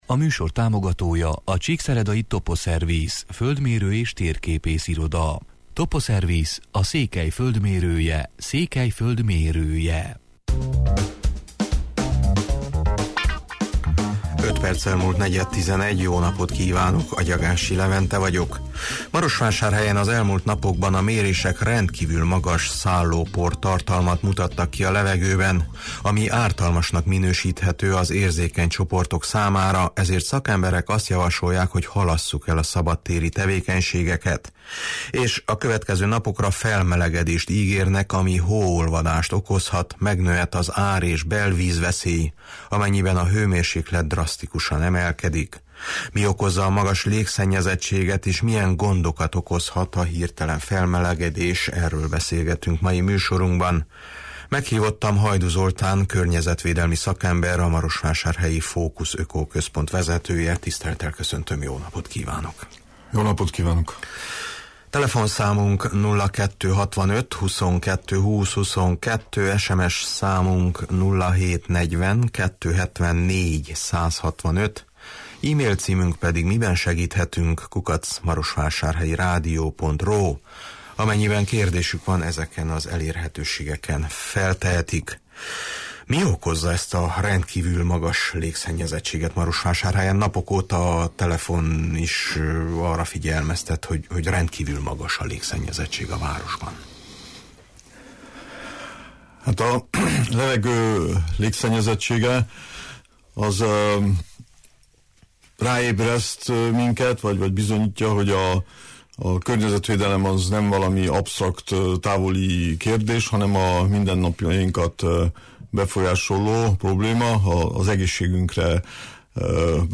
Mi okozza a magas légszennyezettséget, és milyen gondokat okozhat a hirteken felmelegedés – erről beszélgetünk mai műsorunkban.